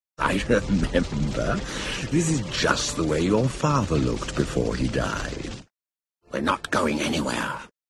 In particular, the widespread and often discussed idea that British or English speech is associated with evil.
Or Jeremy Irons’ villainous lion Scar in Disney’s Lion King: